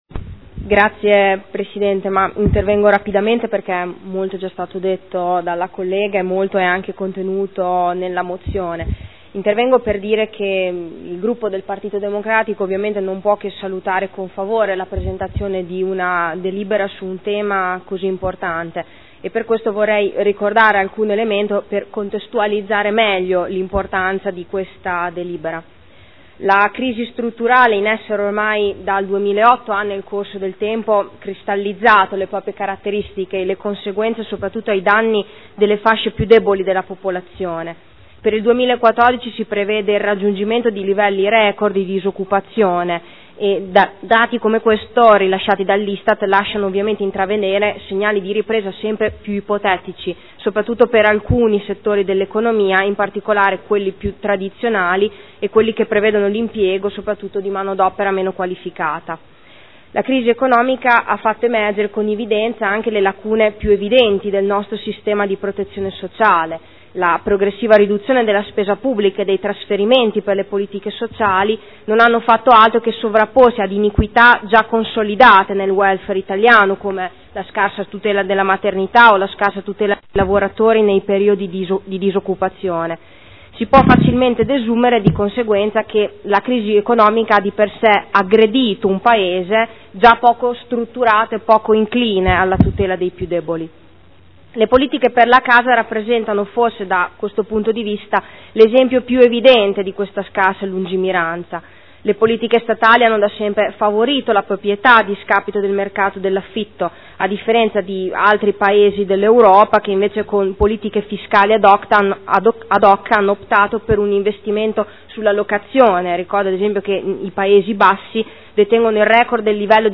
Seduta del 13/05/2013 Dibattito.